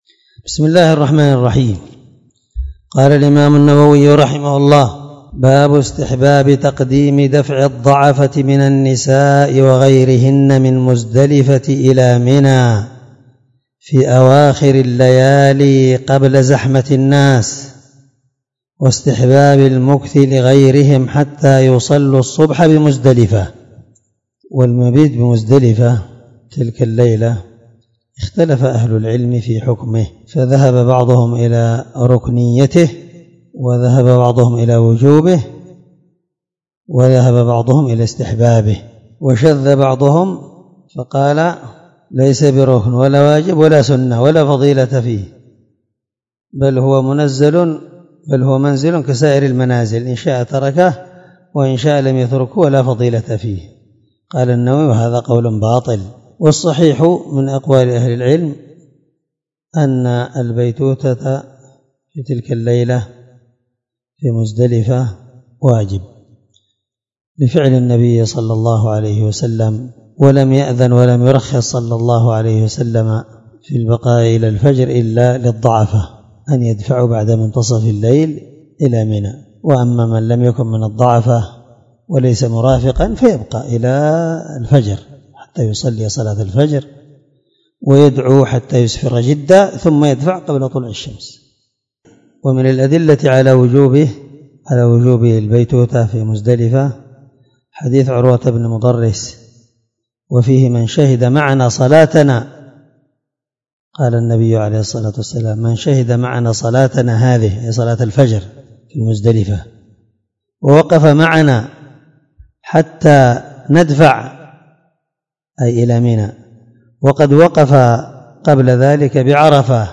الدرس45من شرح كتاب الحج حديث رقم(1290) من صحيح مسلم